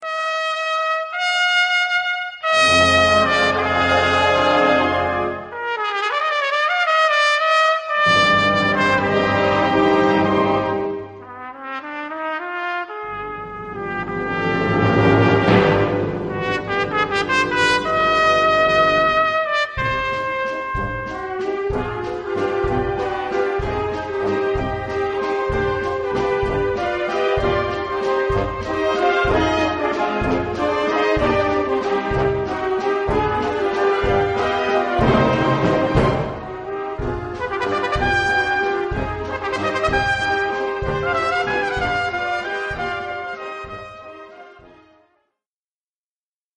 Solo für Trompete und Blasorchester Schwierigkeit
4:13 Minuten Besetzung: Blasorchester Zu hören auf